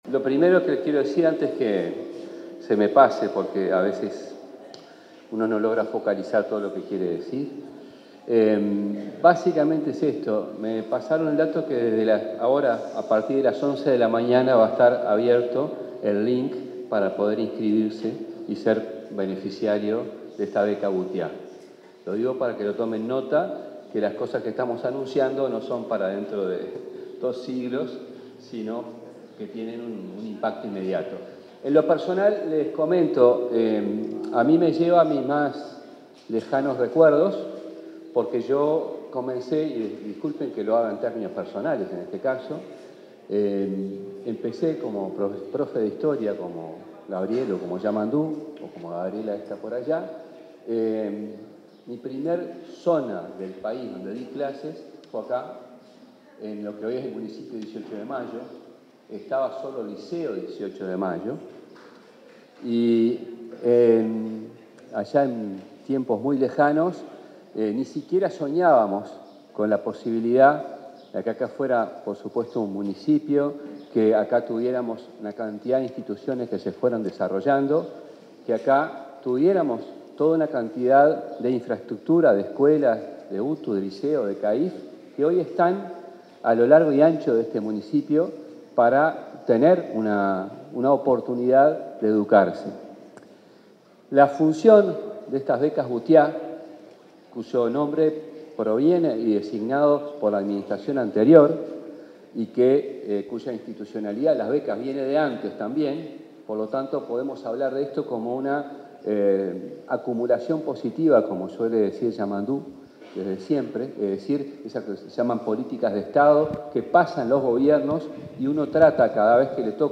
Palabras del ministro de Educación y Cultura, José Carlos Mahía